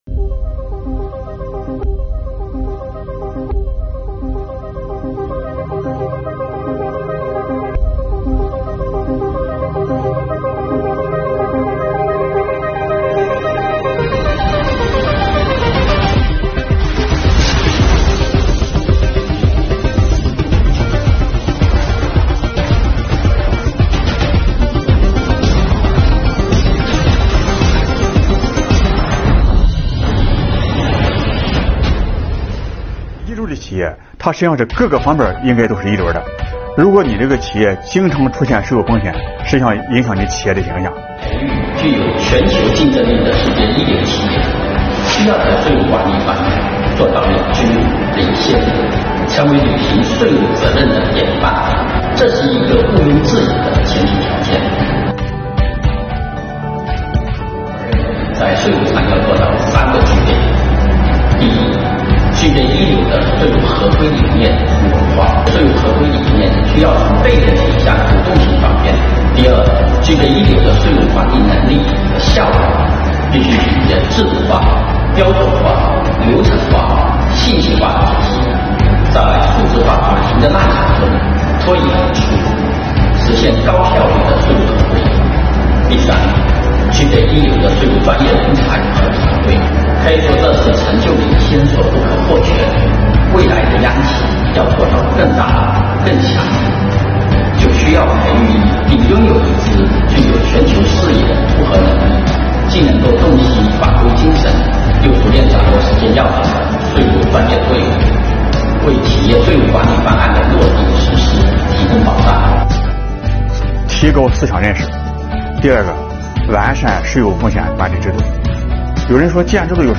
畅聊“创一流路线图”。